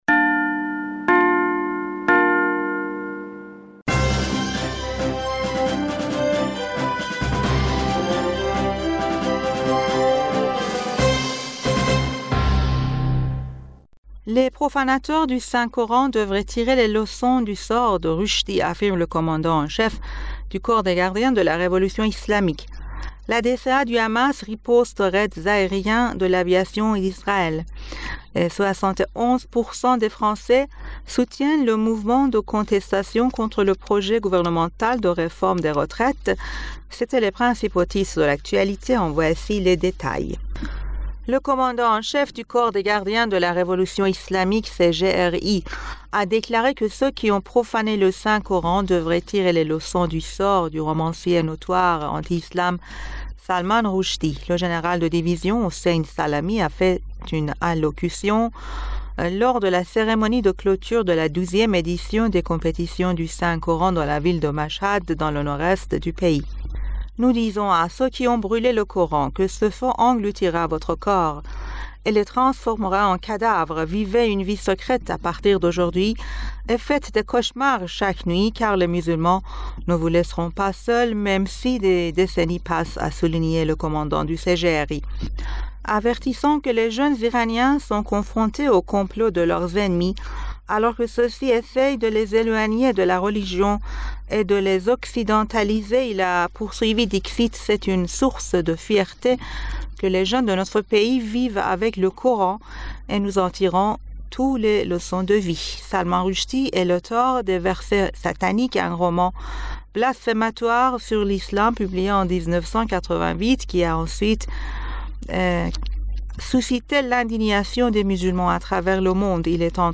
Bulletin d'information du 03 Février